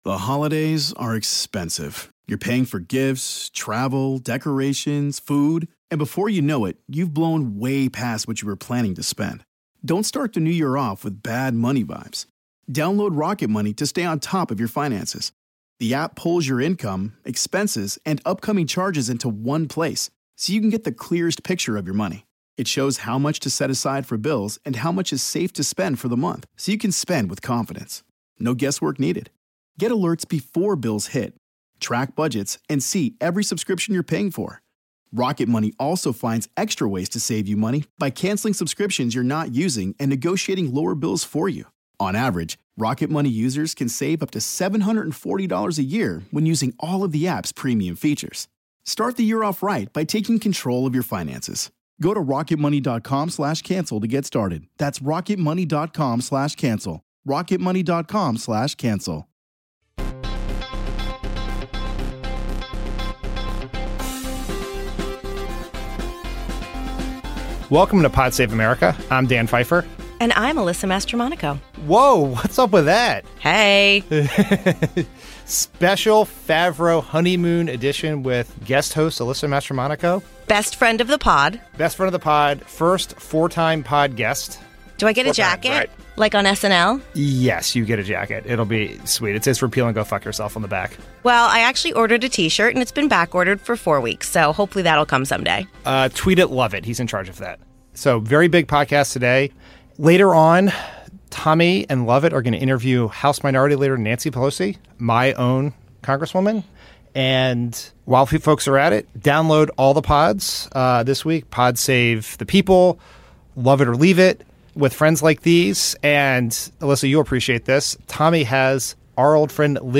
Guest host